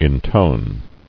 [in·tone]